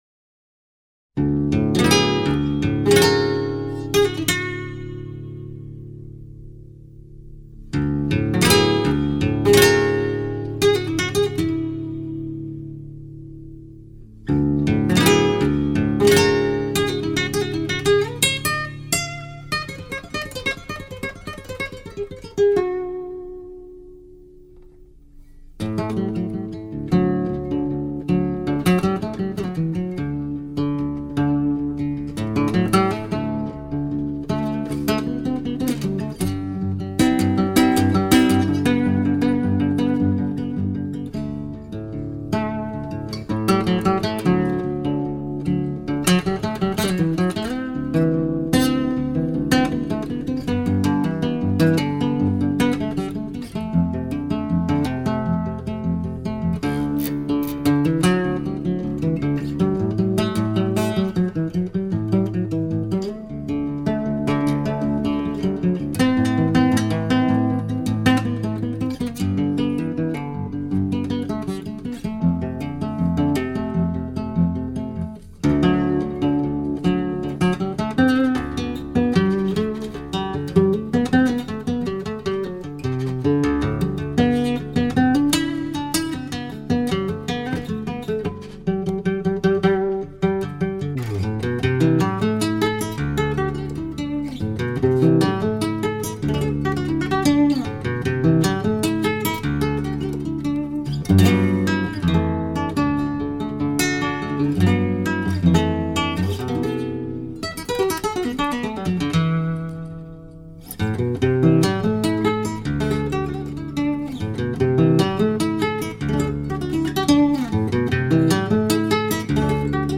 Итальянская группа прогрессивного рока